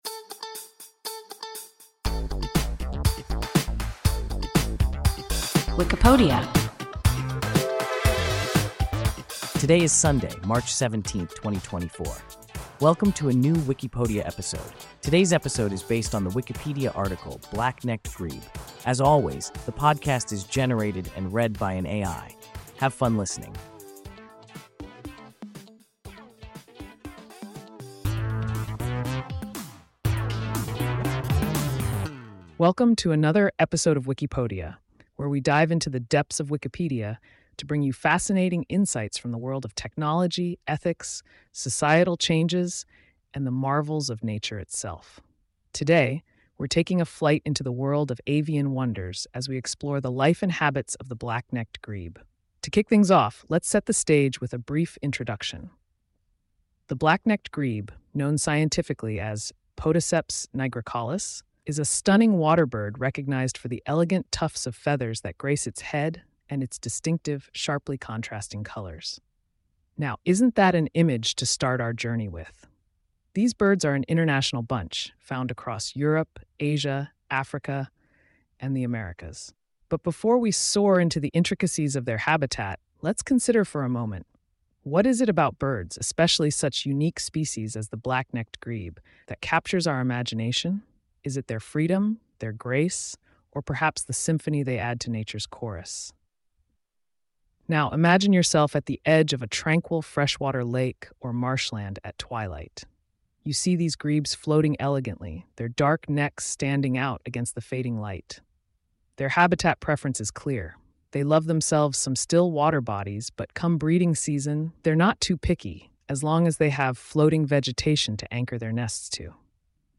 Black-necked grebe – WIKIPODIA – ein KI Podcast